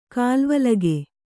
♪ kālvalage